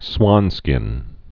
(swŏnskĭn)